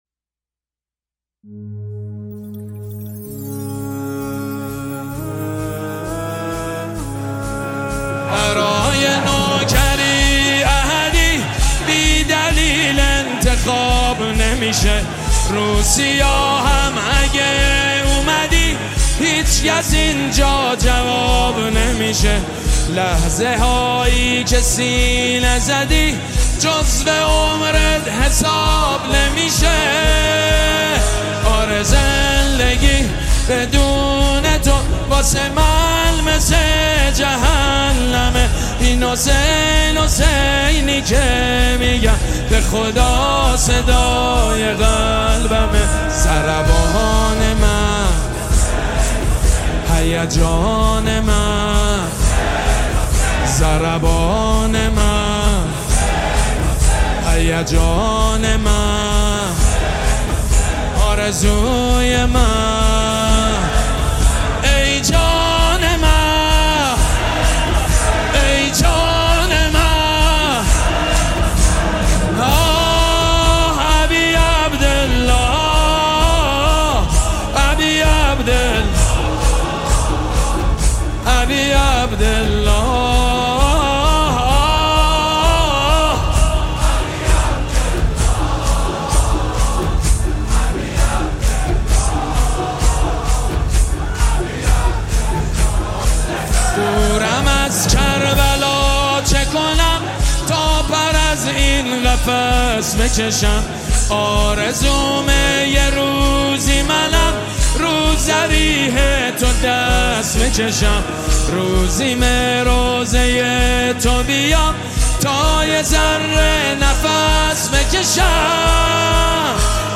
نوای دلنشین